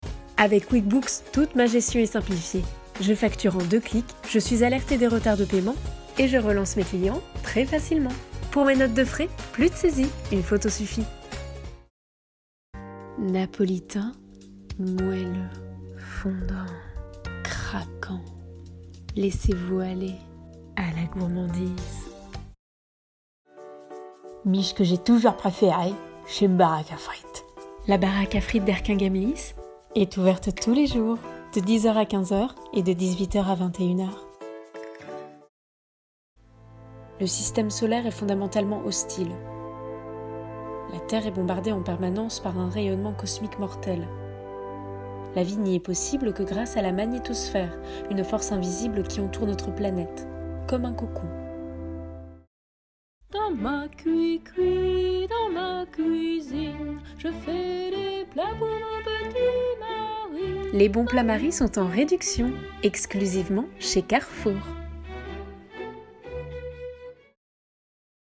Bandes-son
Voix off